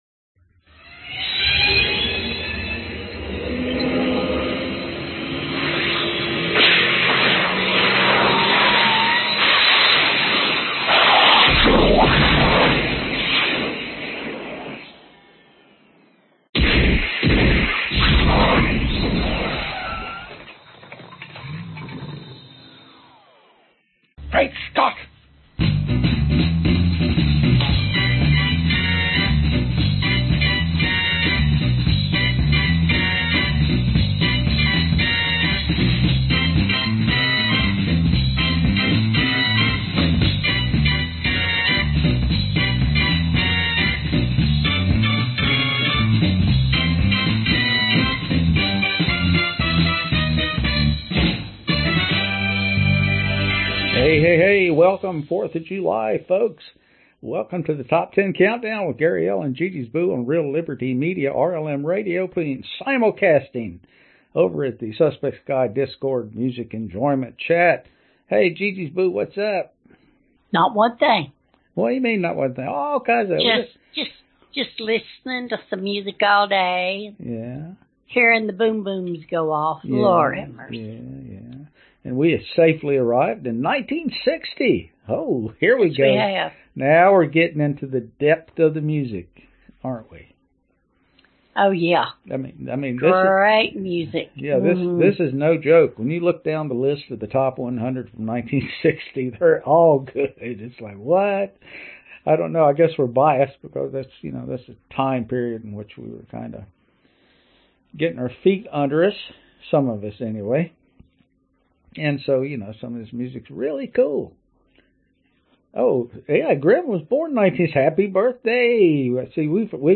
Genre Oldies